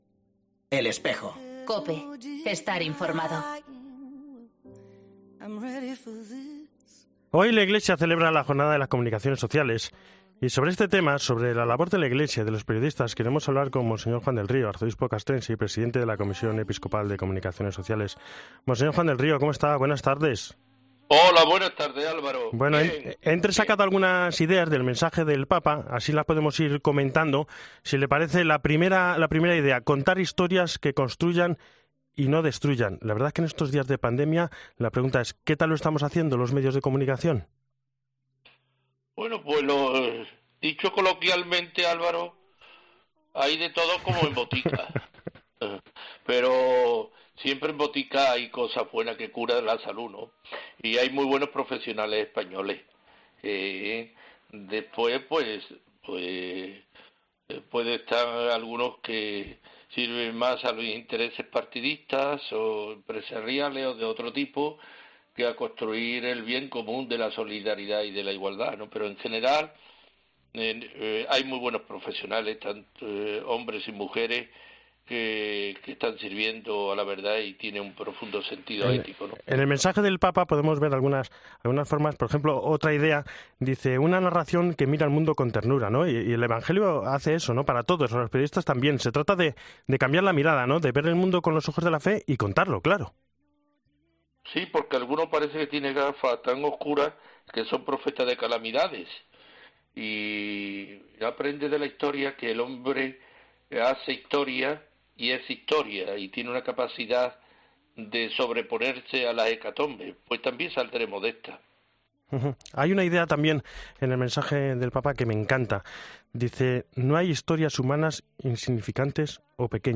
Y sobre este tema, sobre la labor de la Iglesia de los periodistas, ha pasado por los micrófonos de 'El Espejo', Monseñor Juan del Río, Arzobispo Castrense y presidente de la Comisión Episcopal de Comunicaciones Sociales.